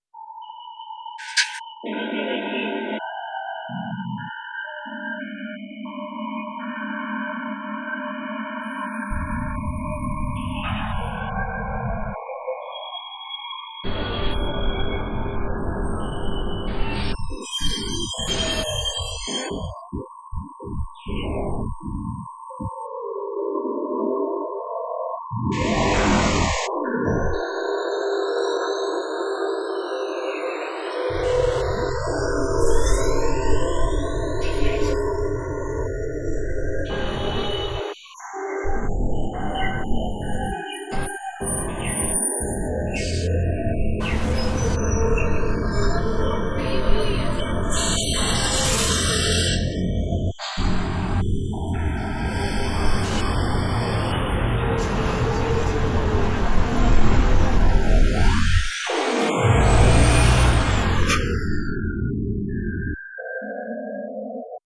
"" This ‘study’ began with a one-minute excerpt of a field recording made at a downtown Vancouver street corner. The original audio document was made with a zoom H6 with the microphones in an x/y configuration and manipulated using the TIAALS software.
And indeed, one can still discern the sounds of the buses, voices, street crossing signals and the busker playing the erhu on the left of the audio image (as well as the small patch of distortion caused by a sudden gust of wind). However, thanks to the filtering process, these everyday sounds may now be engaged with in a radically new way.
Nothing has been ‘added’ to the resulting audio.